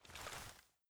thompson_holster.ogg